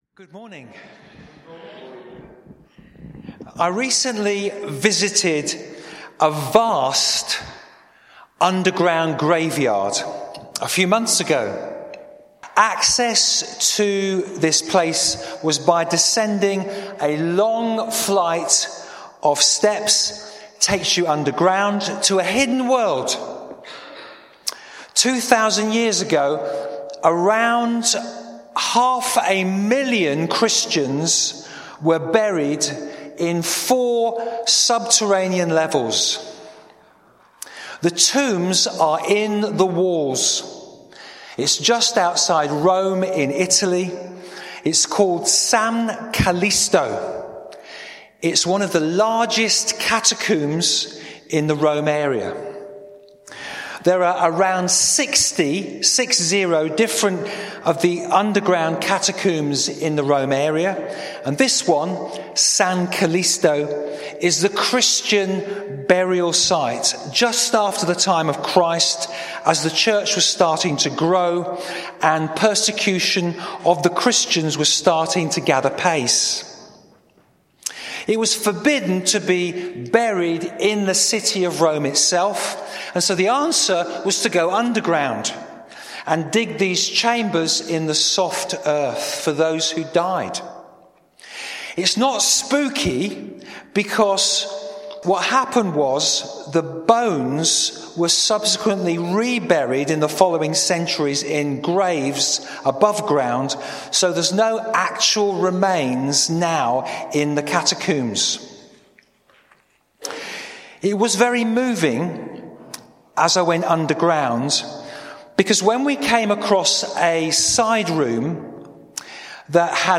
Onesimus - being useful, Bassett Street Sermons